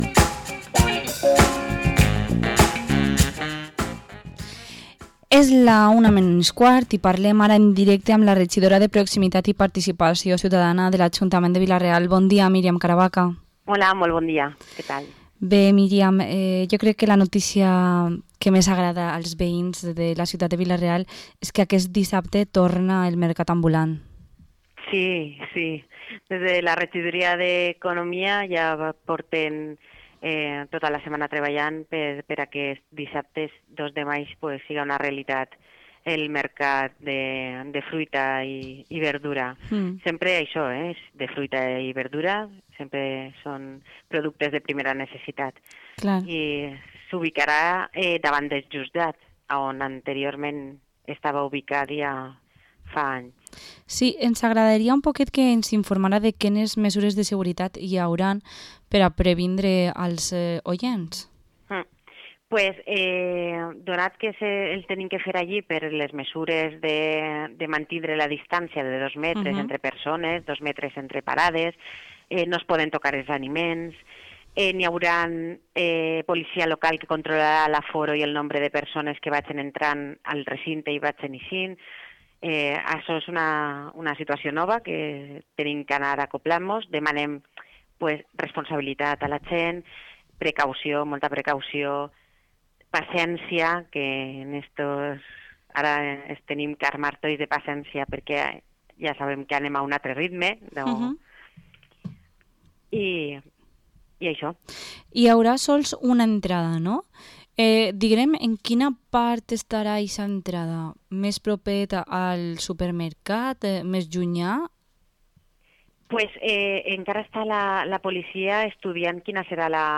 Entrevista a la concejala de proximidad y atención a la ciudadanía de Vila-real, Miriam Caravaca